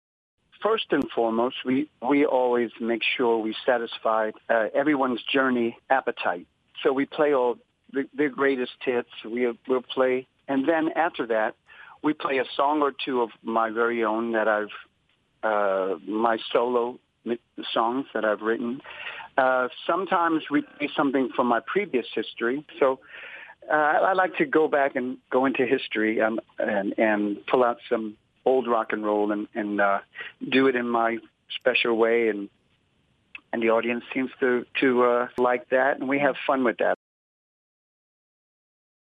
entrevista steve augeri